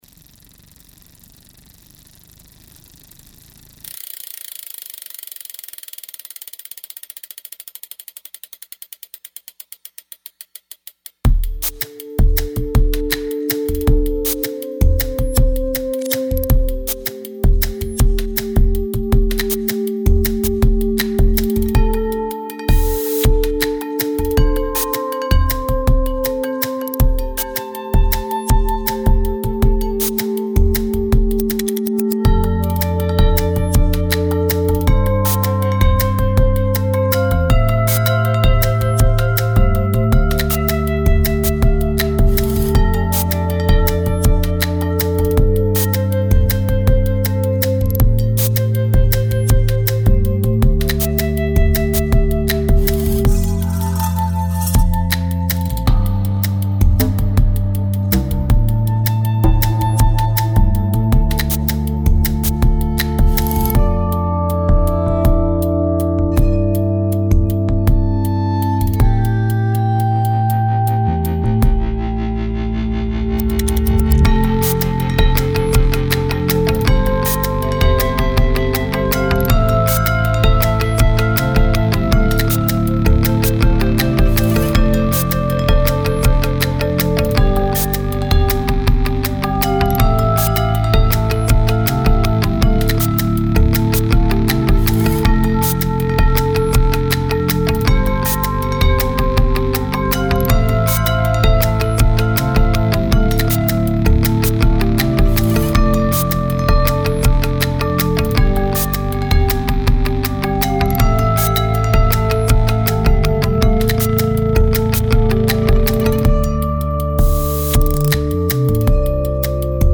creates music with everyday objects
a song created sans piano, drums, guitars or voice…